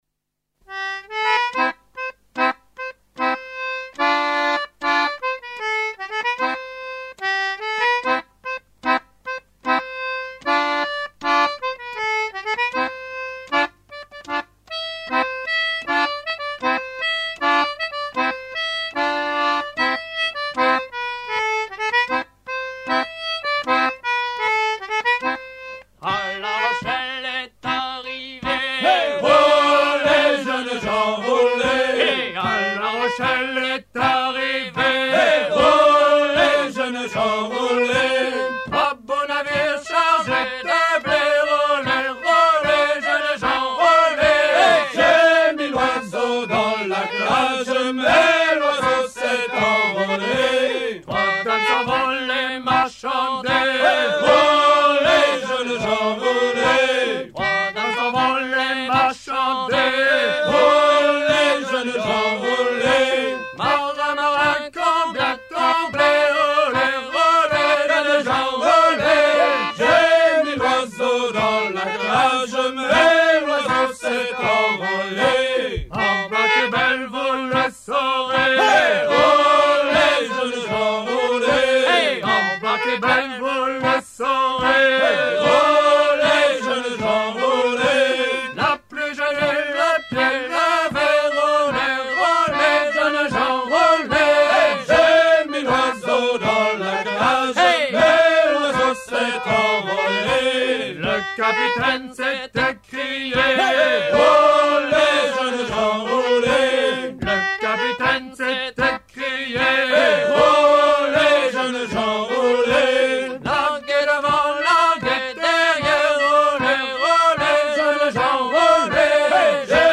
Fonction d'après l'analyste gestuel : à virer au cabestan ;
Genre laisse